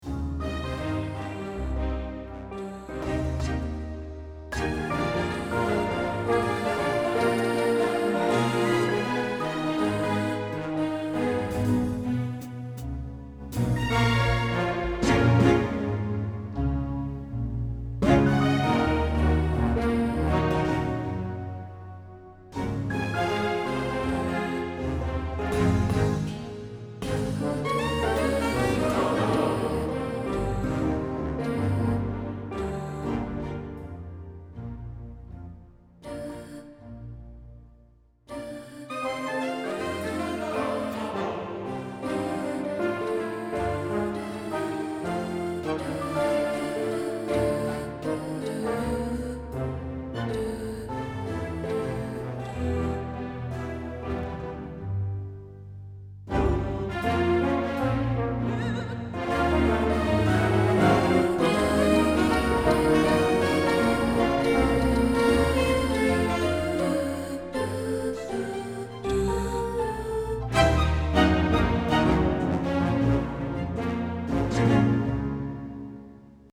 Orchestra & Combo
Gospel Singers, Female Choir, Male Choir